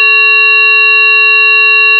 You can listen here to different sound signals composed of the same fundamental frequency (440 Hz) and the same harmonic frequencies (880 Hz, 1320 Hz, 1760 Hz, 2200 Hz) but with different harmonic amplitudes.